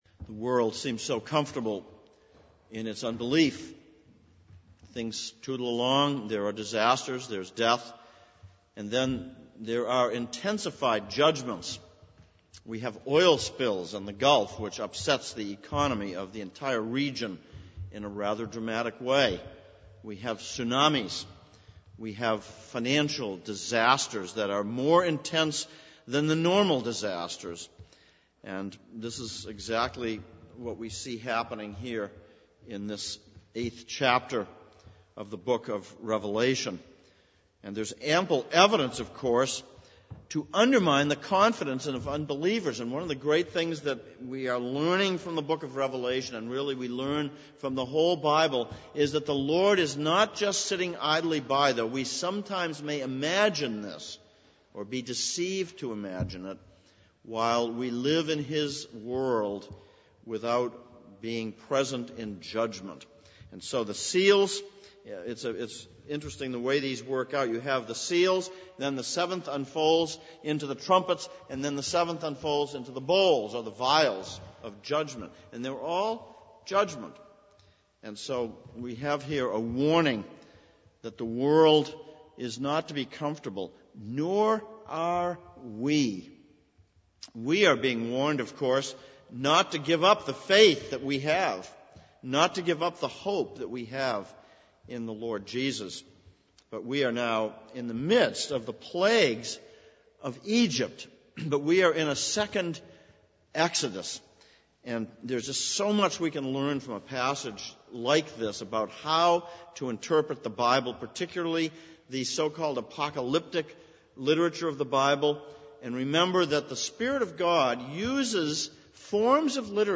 Ezekiel 38:14-23 Service Type: Sunday Evening Revelation 8:1-13